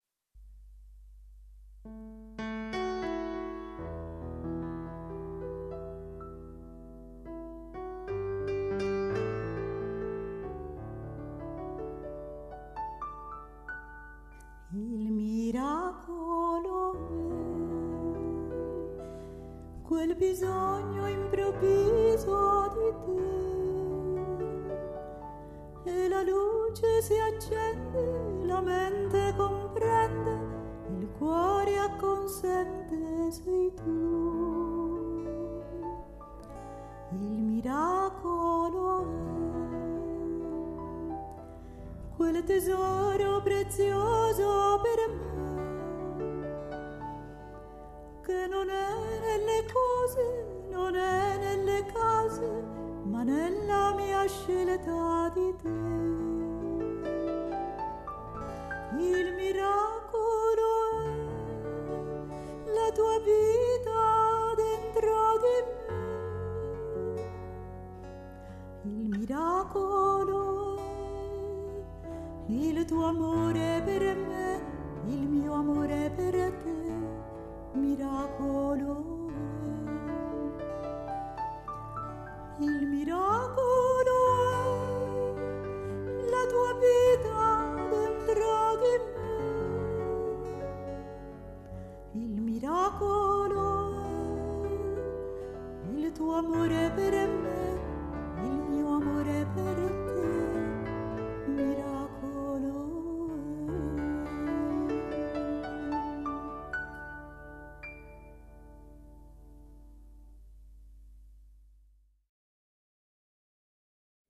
nr. 1 Preghiera mp3 – Miracolo canto mp3